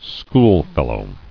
[school·fel·low]